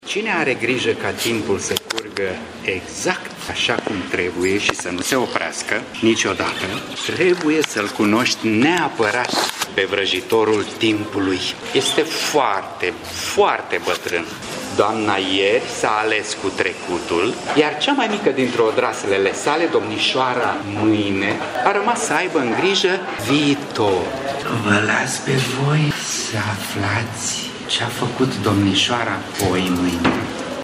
Prima parte a zilei de sâmbătă de la târgul de carte Bookfest, ce are loc la acest sfârșit de săptămână la Teatrul Național din Tîrgu-Mureș, a fost dedicată copiilor.
a interpretat scurte fragmente din povestea personificată a Timpului